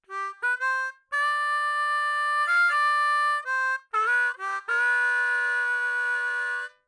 口琴练习剪辑 " 口琴裂缝13
描述：这是我在练习课的即兴部分中演奏并开始打磨的作品。
Tag: 口琴 钥匙 C